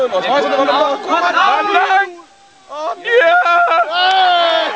Bei den aufgenommenen Torsequenzen fallen im Hintergrund immer wieder irgendwelche komischen Kommentare, man hört Jubelschreie oder wilde Diskussionen...